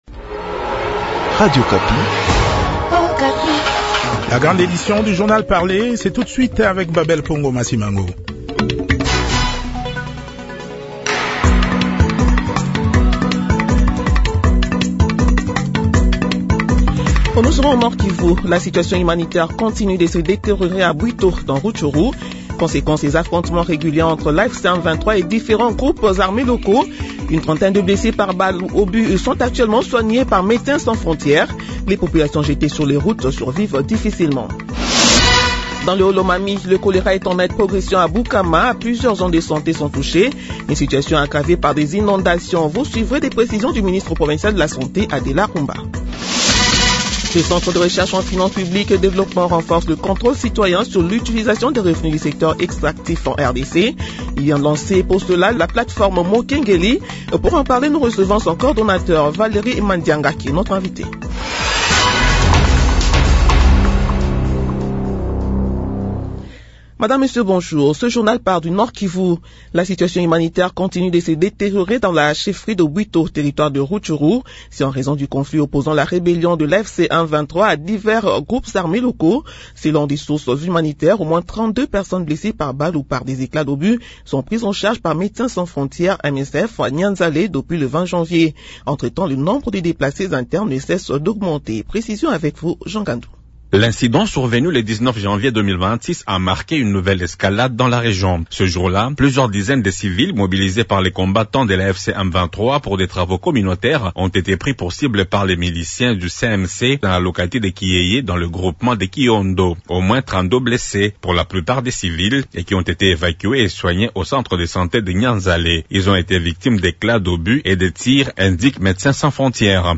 Journal midi de vendredi 23 janvier 2026